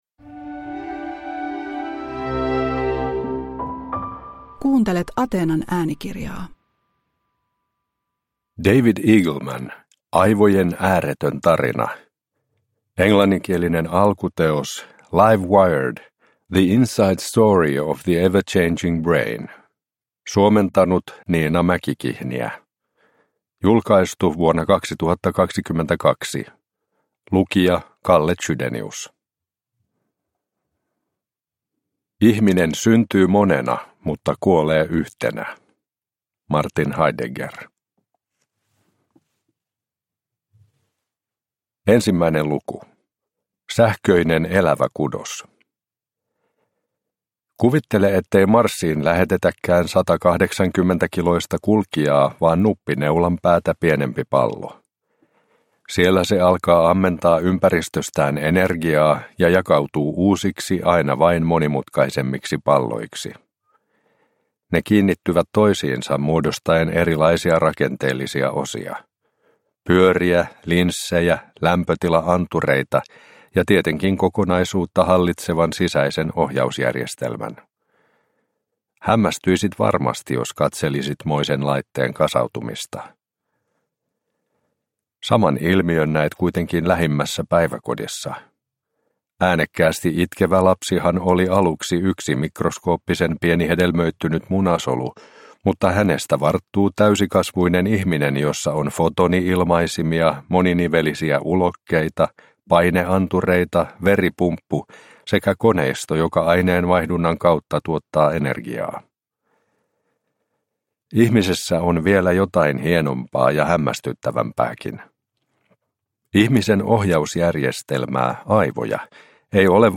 Aivojen ääretön tarina – Ljudbok – Laddas ner